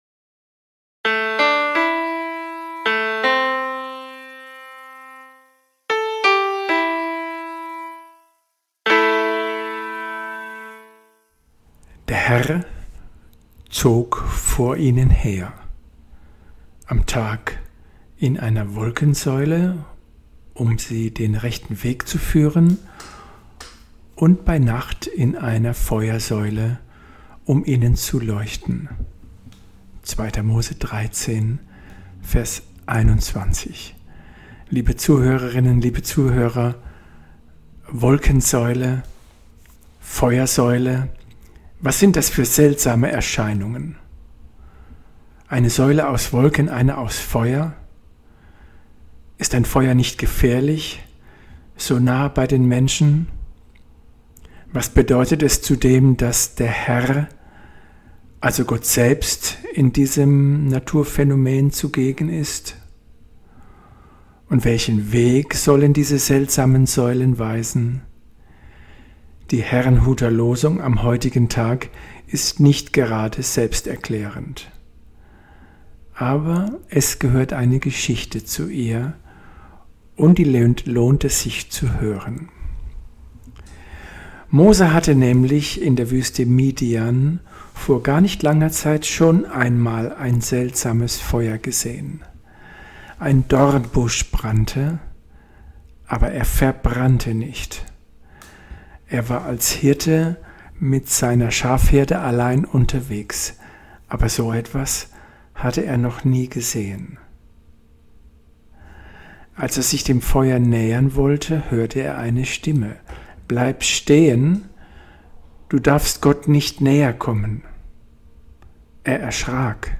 Losungsandacht für Samstag, 04.06.2022